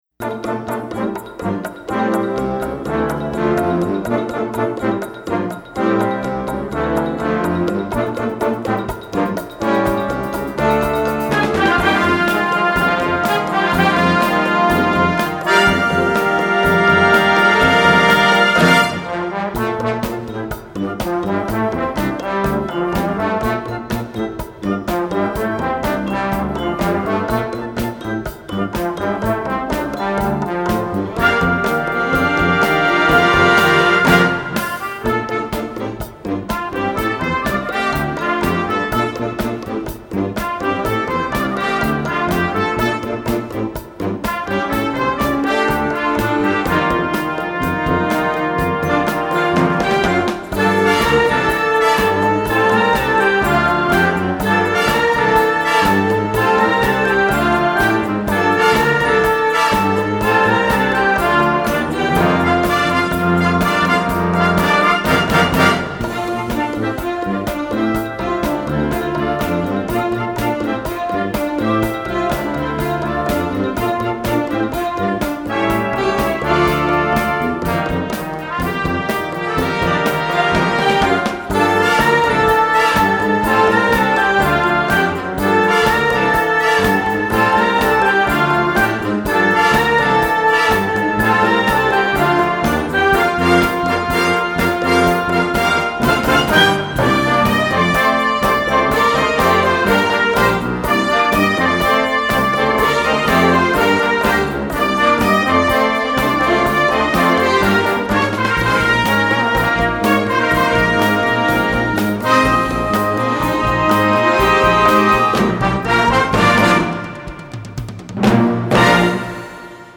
Besetzung: Blasorchester
Die klassische Funk-Melodie aus den 70ern